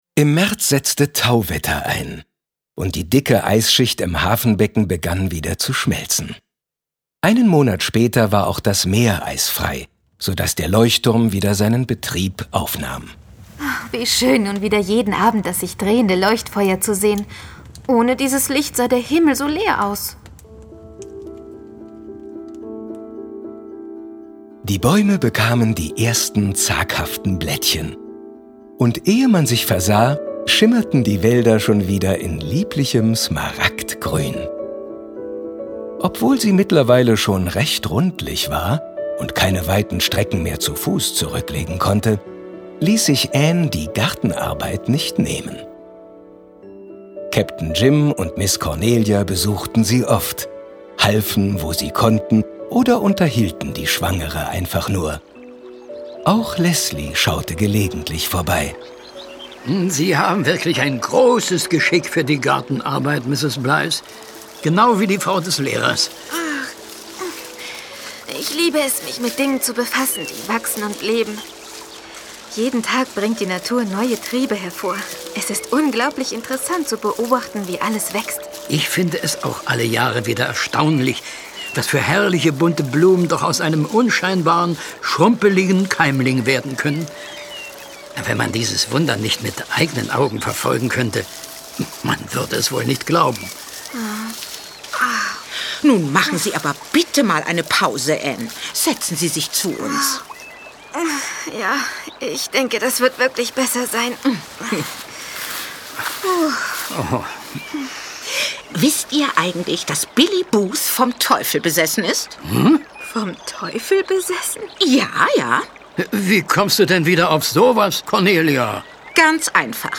Hörspiel.